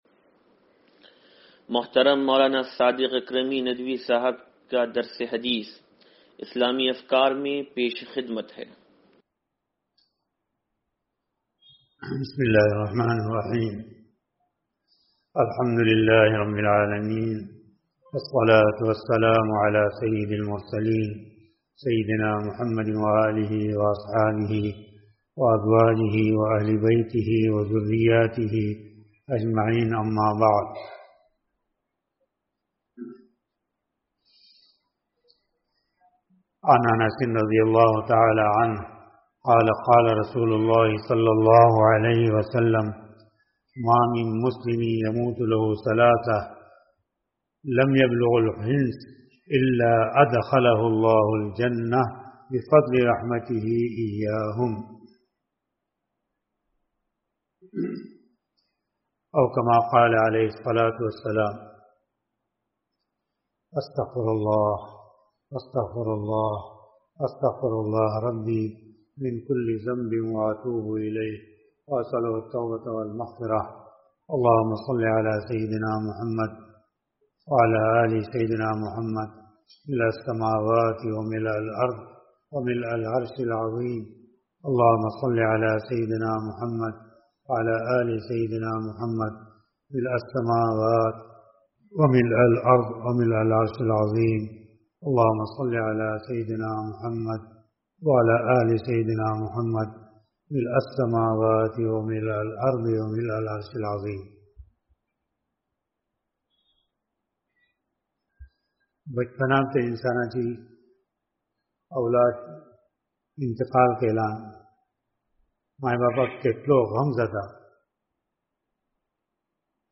درس حدیث نمبر 0747